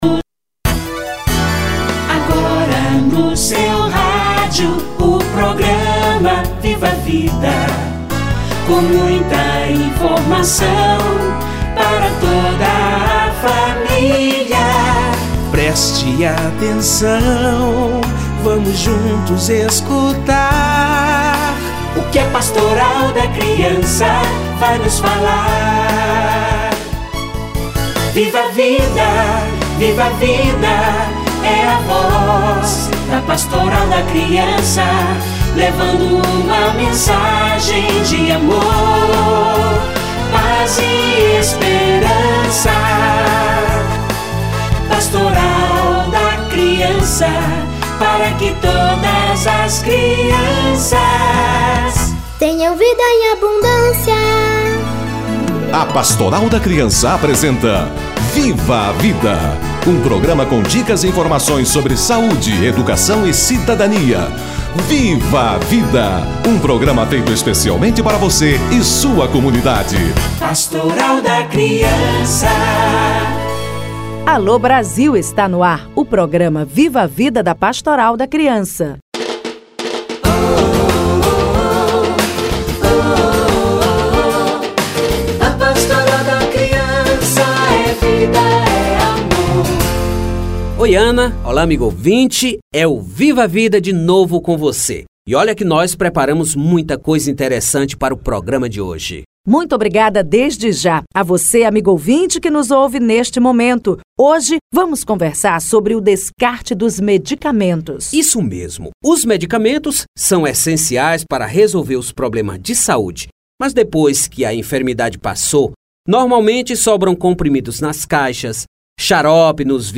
Descarte de medicamentos - Entrevista